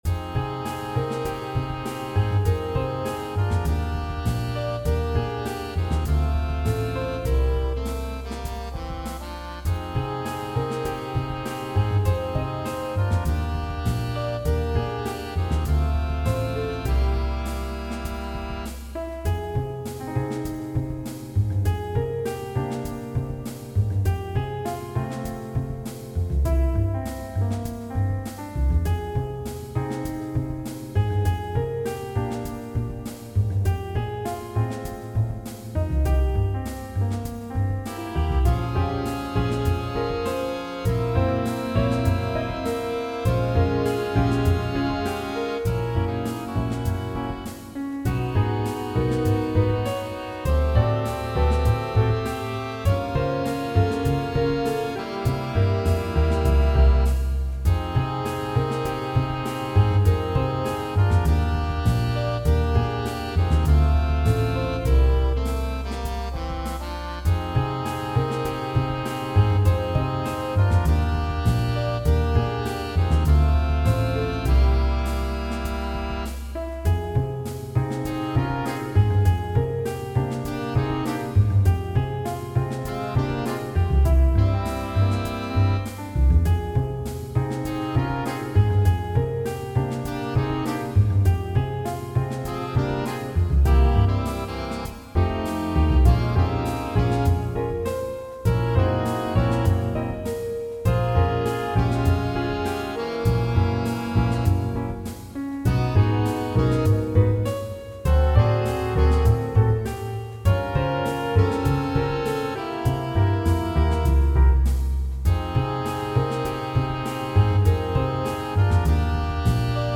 Vocal, Trumpet, Sax, Trombone, Piano, Bass, Drums
All audio files are computer-generated.